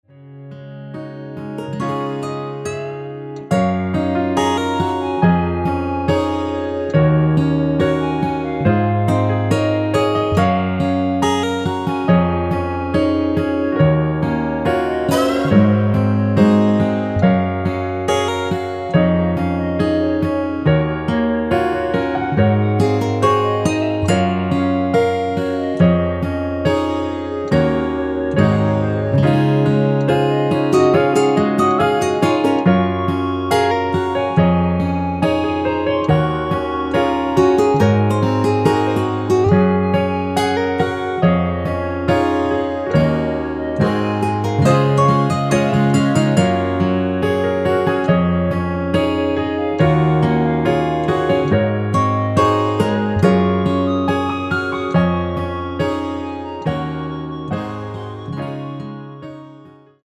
원키에서(+3)올린 멜로디 포함된 MR입니다.
여성분이 부르실수 있는 키로 제작 하였습니다.(미리듣기 참조)
Db
앞부분30초, 뒷부분30초씩 편집해서 올려 드리고 있습니다.
중간에 음이 끈어지고 다시 나오는 이유는